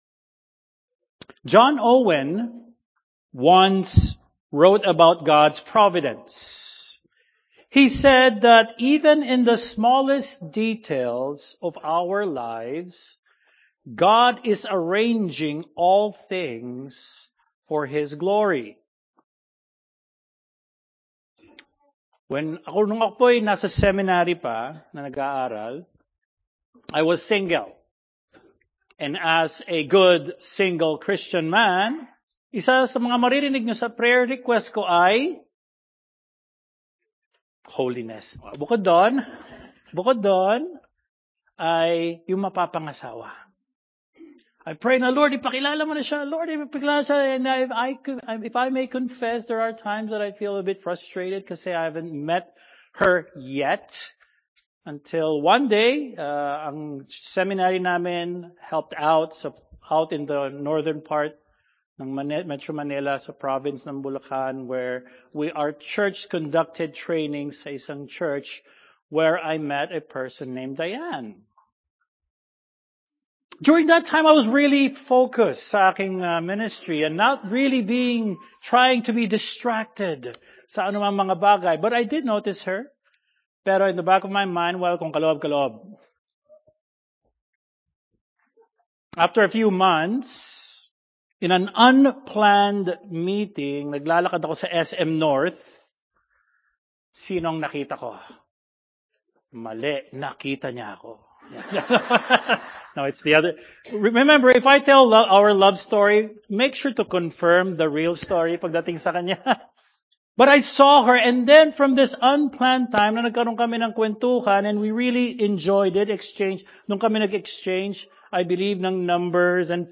Continuation of the sermon series in the book of Genesis